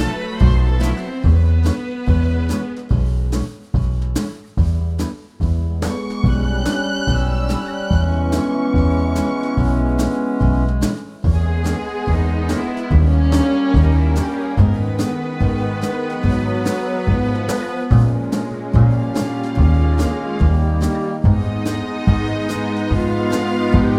Live Crooners 3:39 Buy £1.50